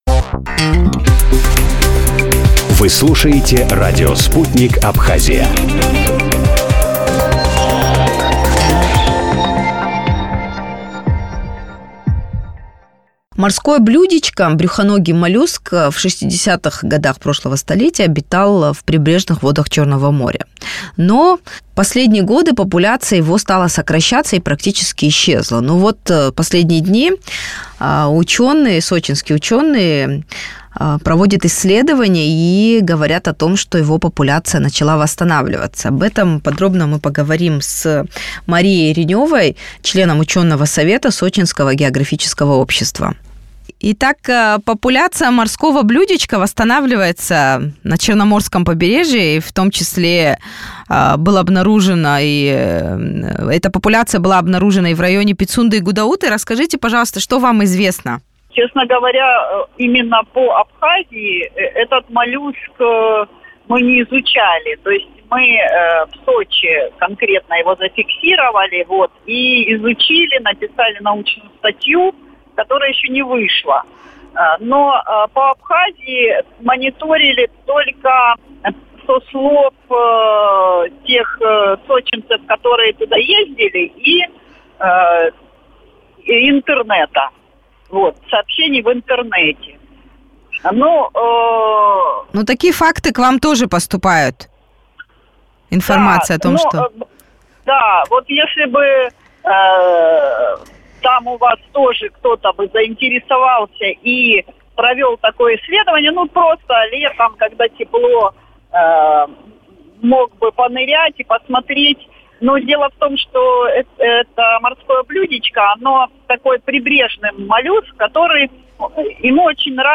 в эфире радио Sputnik прокомментировала сообщение о возращении брюхоногого моллюска Морское блюдечко в Черное море.